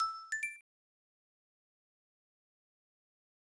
dong.wav